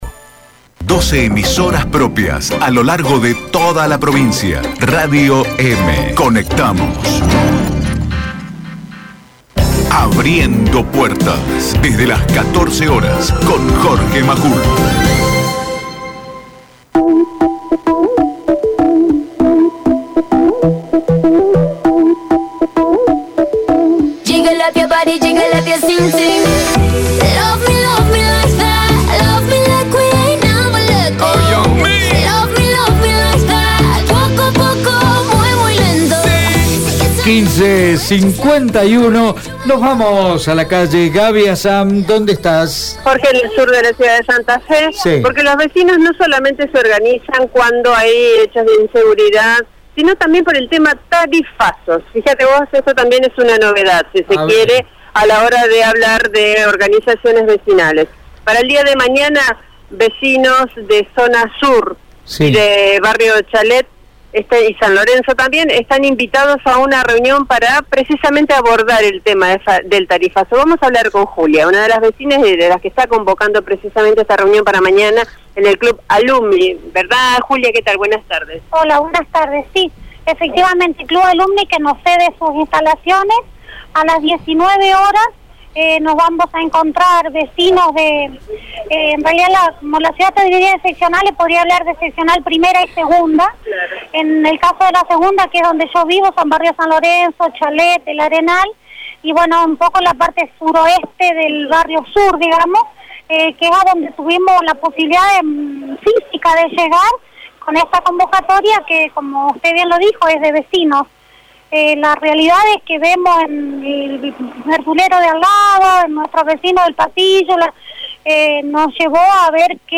Los vecinos de los barrios Chalet, San Lorenzo, El Arenal, entre otros se reunirán a las 19 Club Alumni para debatir sobre el tarifazo. Una de las vecinas consultadas explicó que «la verduleria del barrio atiende con la luz apagada».